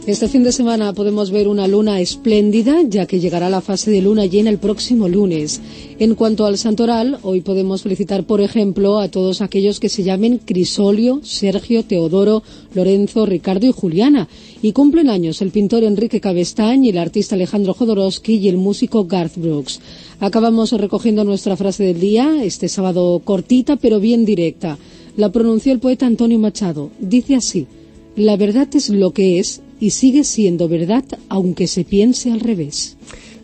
Entreteniment
FM